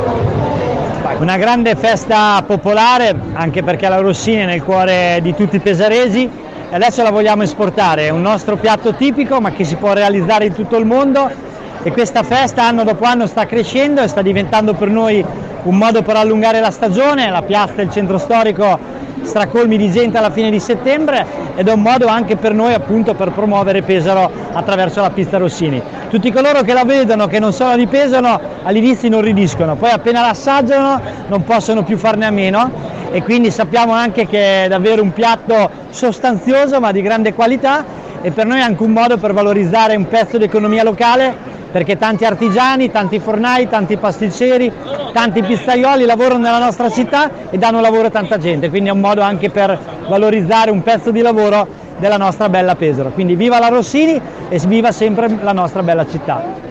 Sono i numeri da record della seconda edizione del Festival della pizza pesarese, che nel week end appena trascorso, ha attirato in piazza del Popolo migliaia di persone. Le parole di soddisfazione del Sindaco di Pesaro Matteo Ricci.